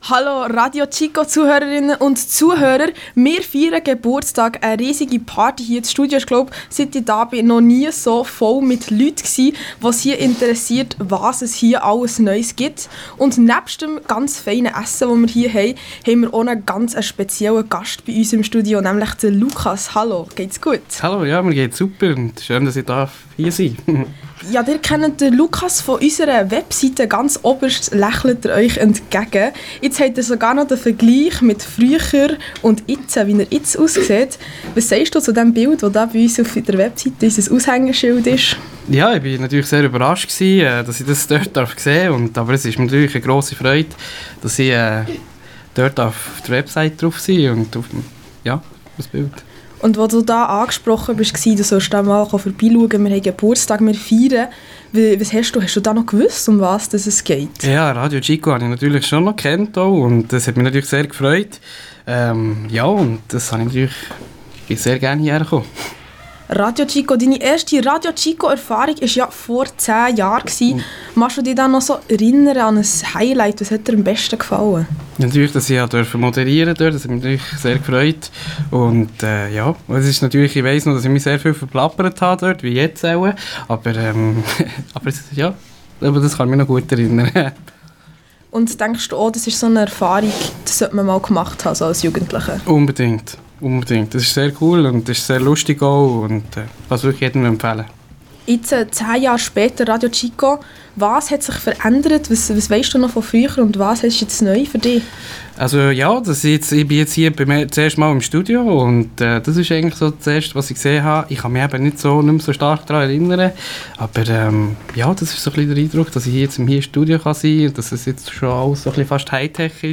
Am 8. Januar haben wir in unserem Studio den 10. Geburtstag von RadioChico Schweiz gefeiert.
Interview